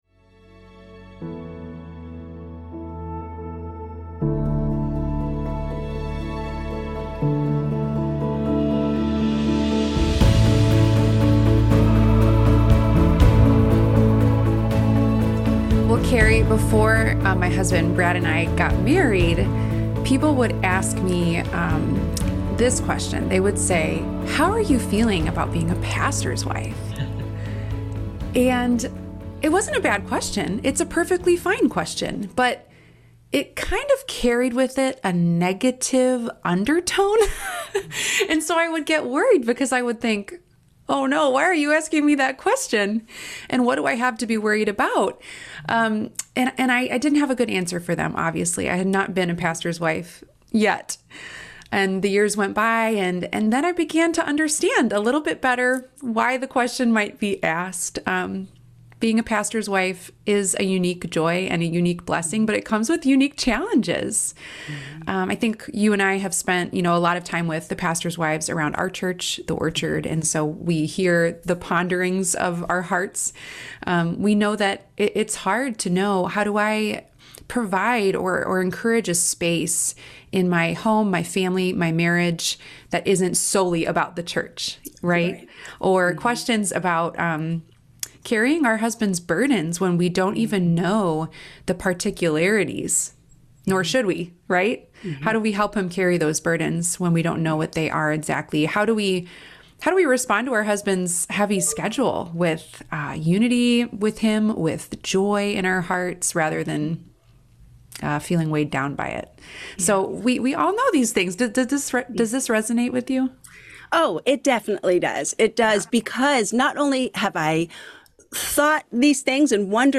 You’ll be encouraged by this conversation, as it’s full of biblical and practical wisdom on loving your husband well and supporting him in ministry while prioritizing your own relationship with God.